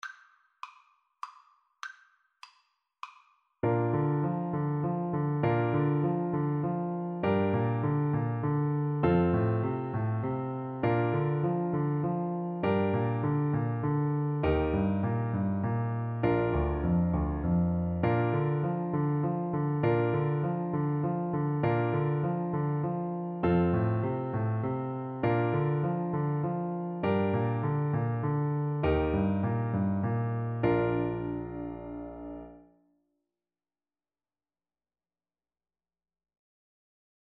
Play (or use space bar on your keyboard) Pause Music Playalong - Piano Accompaniment Playalong Band Accompaniment not yet available reset tempo print settings full screen
"Arirang" is a Korean folk song, often considered as the unofficial national anthem of Korea.
Bb major (Sounding Pitch) (View more Bb major Music for Tuba )
3/4 (View more 3/4 Music)